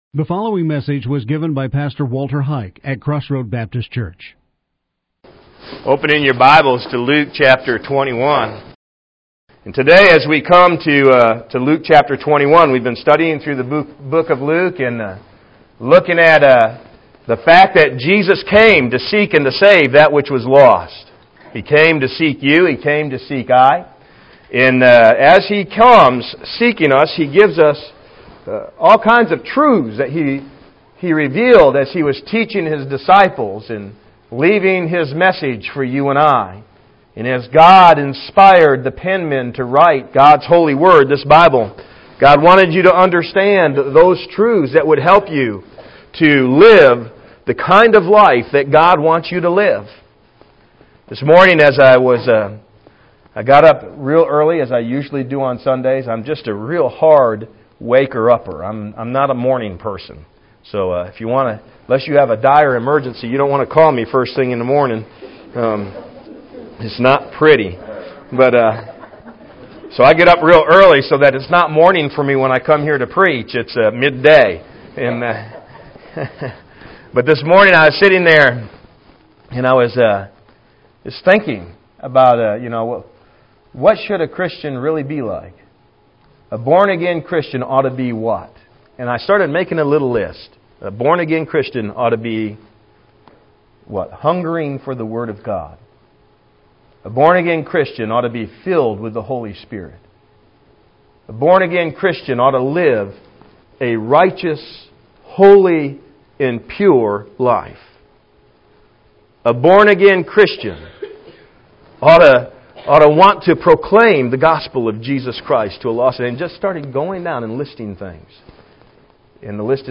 What Shall These Things Be Outline and Audio Sermon